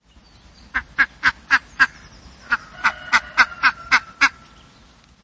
Звуки и голос уток
Звук крякания утки